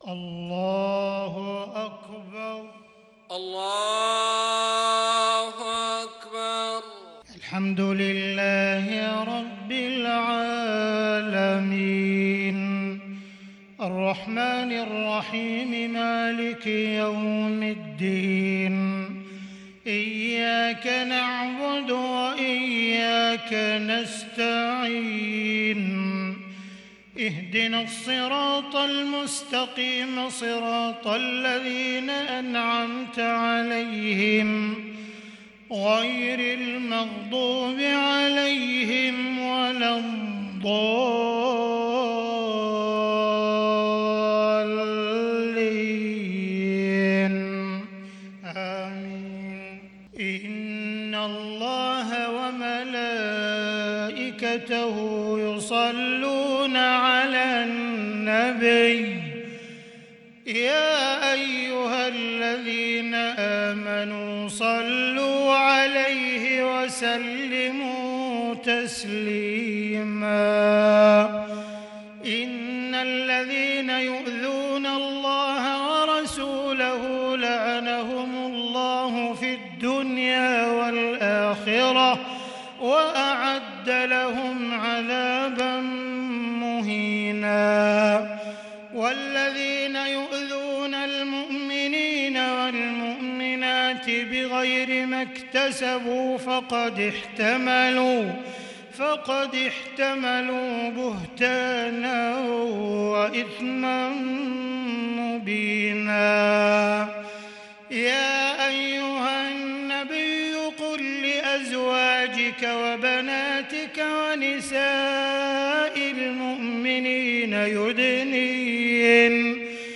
صلاة العشاء للشيخ عبدالرحمن السديس 1 ذو القعدة 1442 هـ
تِلَاوَات الْحَرَمَيْن .